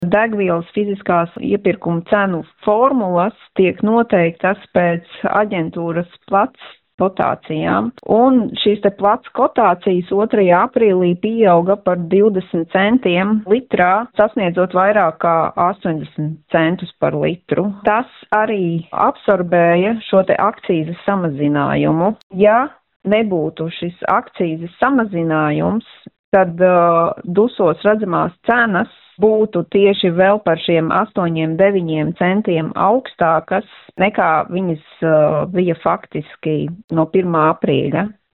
" situāciju intervijā Skonto mediju grupai